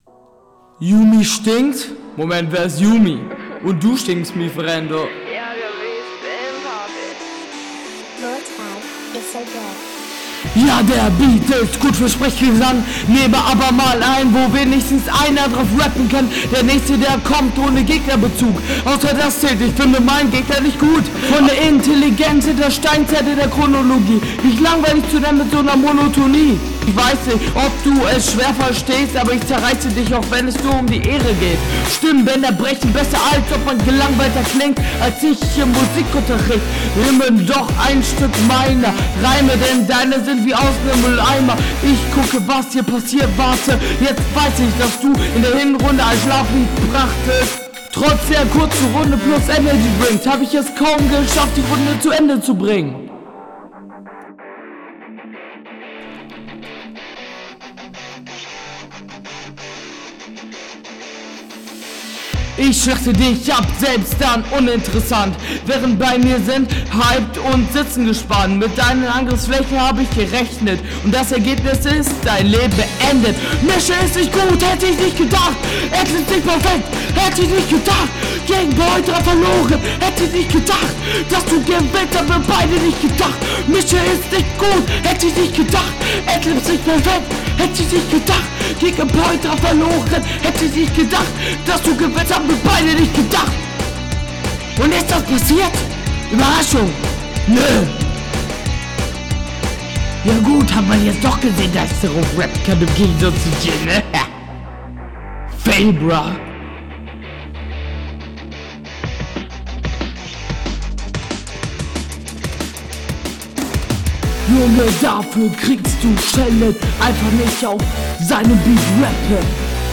Hat der wirklich einfach nen andern beat gepickt?